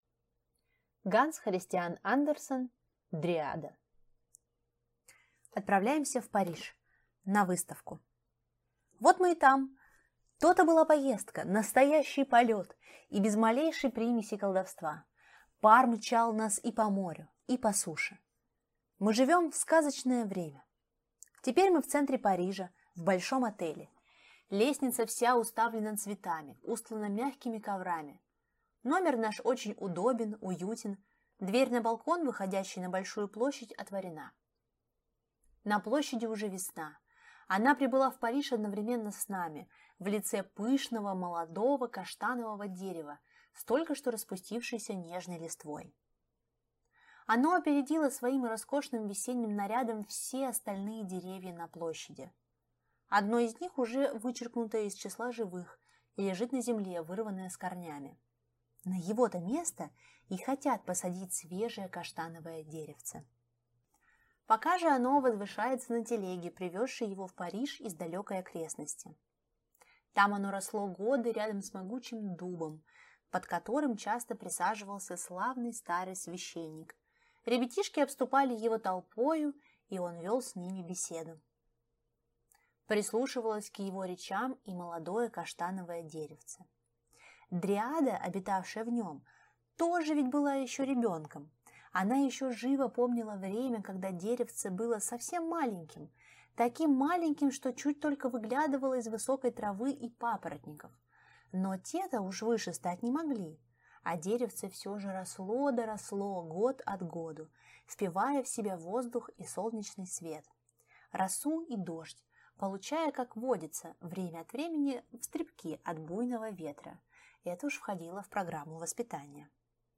Аудиокнига Дриада | Библиотека аудиокниг